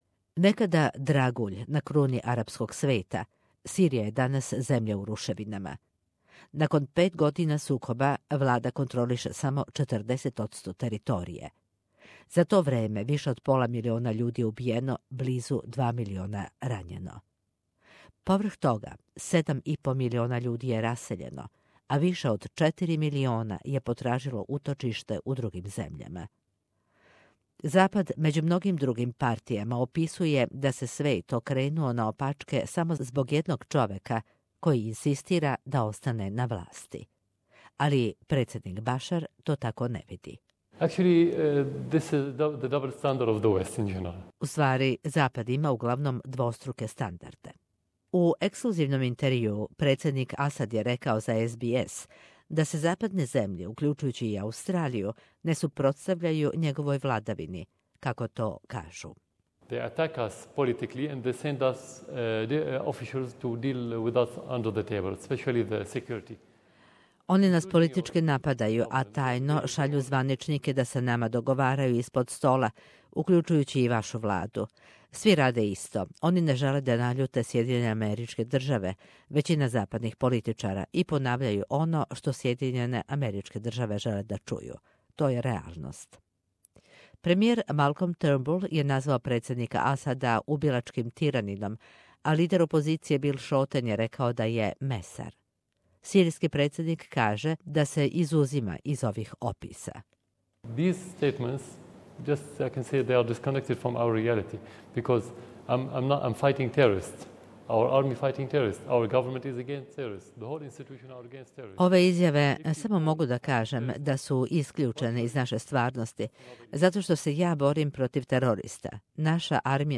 извештава из Дамаска.